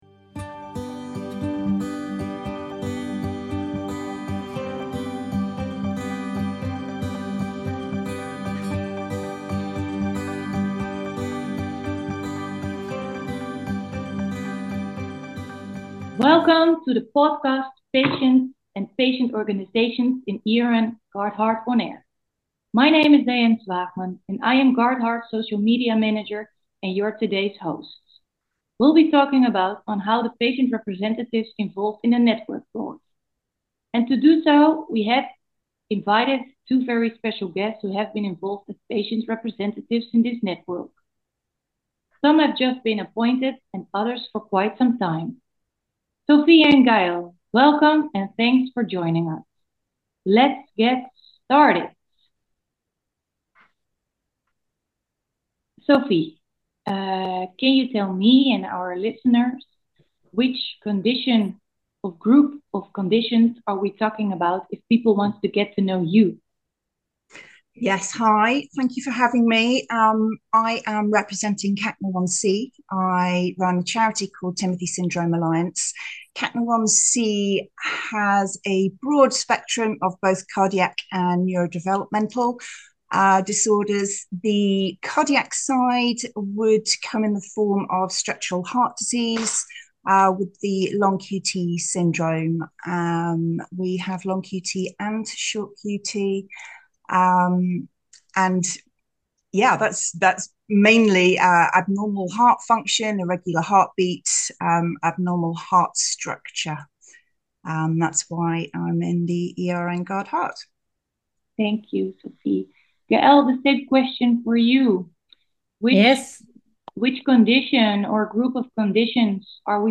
In March 2024 ERN GUARDHEART started with a podcast series in which all ePags or patient representatives are requested to participate. The idea is that in each recording, we are interviewing two representatives as a duo.